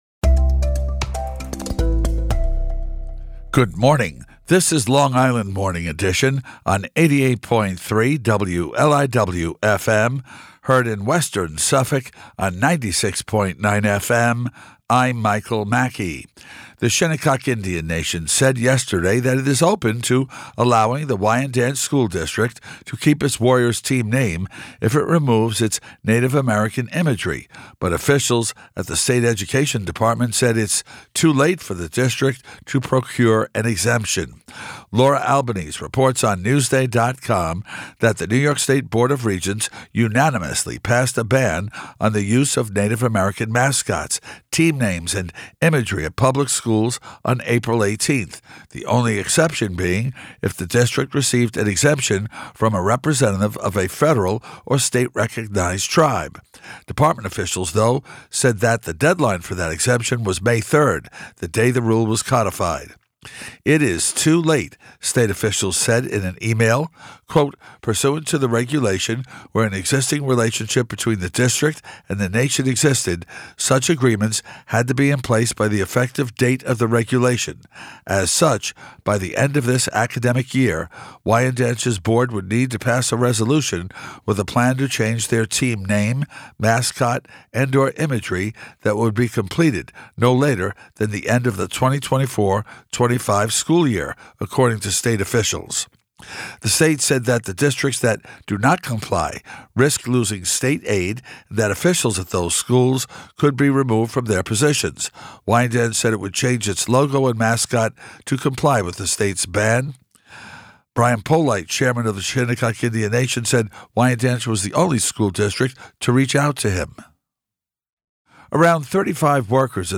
newscast-converted.mp3